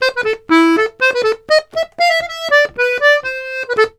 S120POLKA3-L.wav